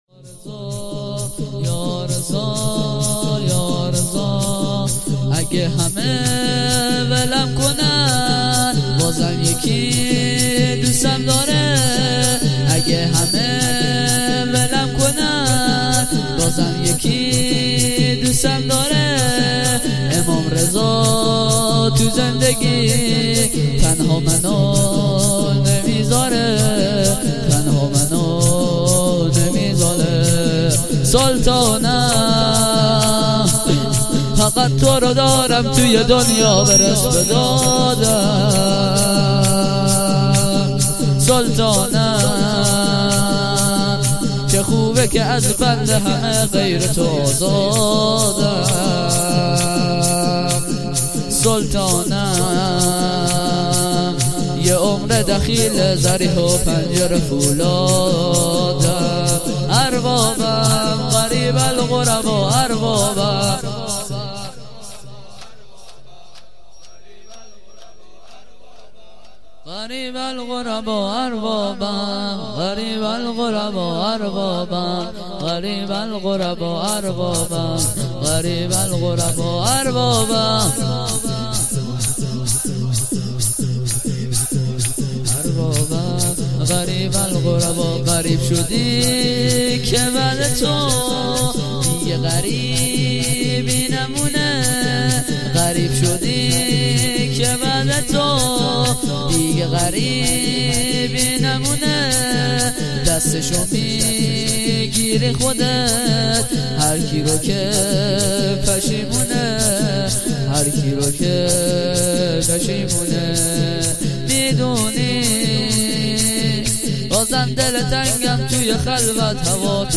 جلسه هفتگی